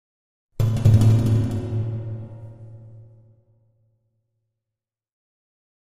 Metal Drum - Several Hits Version 3